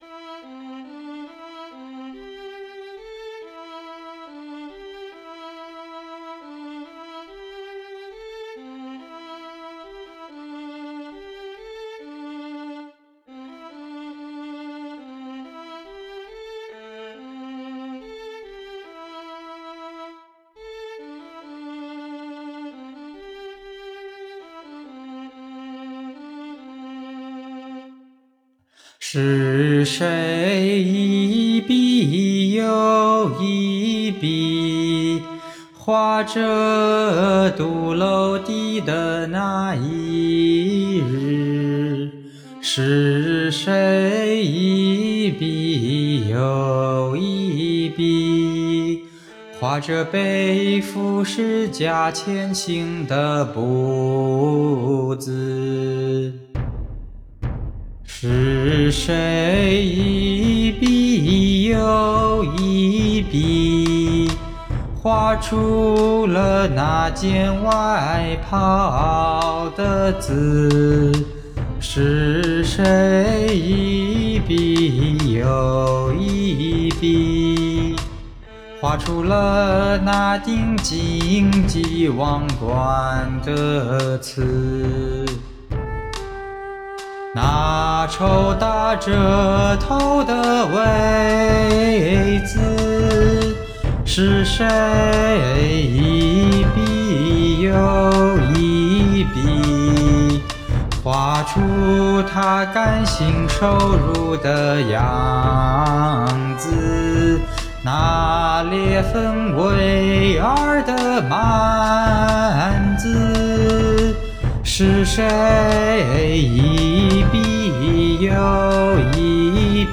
献唱/赞美新歌《活画》
原创-活画-速度140-C大调-混音完成文件.mp3